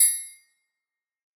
Perc (Triangle).wav